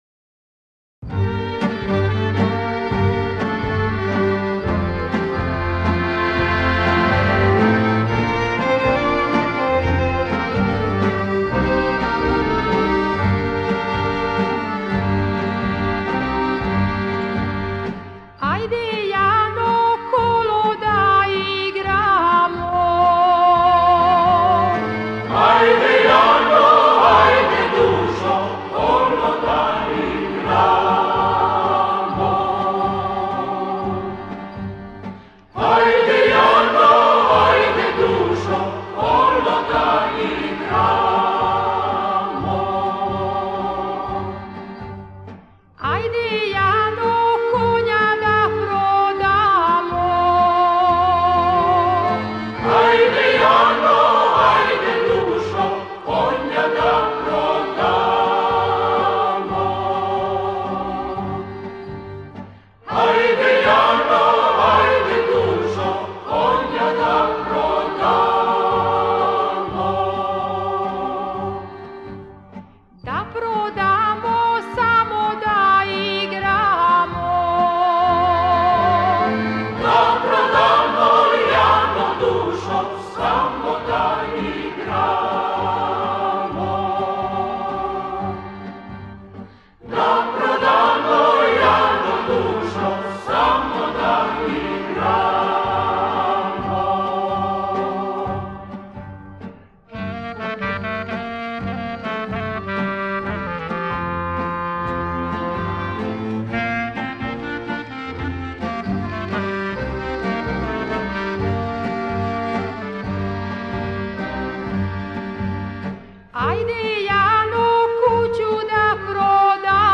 Ajde Jano (wyk.serbskie) - mp3-plik do ściągnięcia